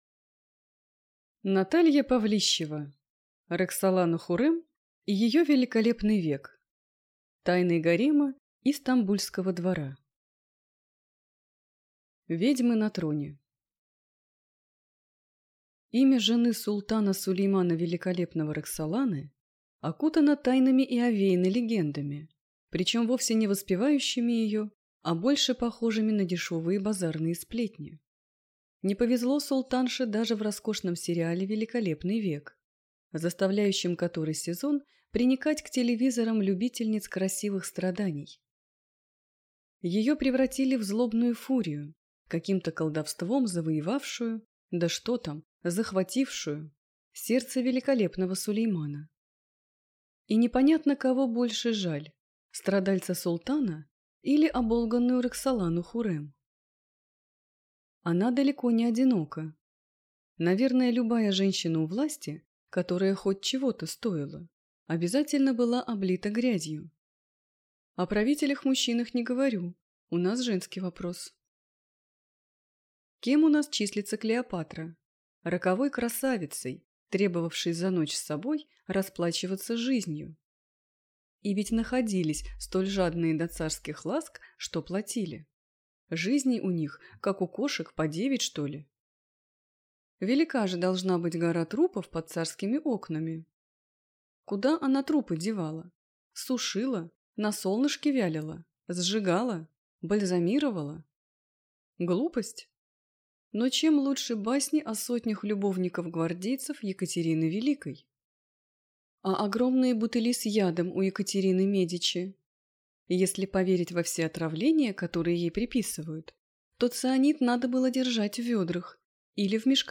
Аудиокнига Роксолана-Хуррем и ее «Великолепный век». Тайны гарема и Стамбульского двора | Библиотека аудиокниг